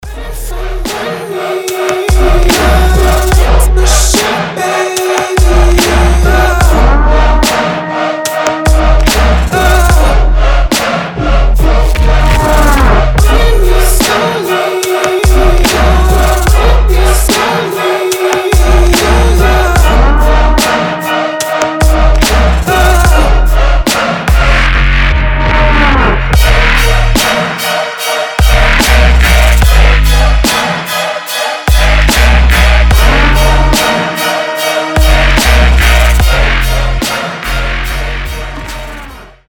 • Качество: 320, Stereo
громкие
красивый мужской голос
Electronic
мощные басы
труба
эпичные
тревога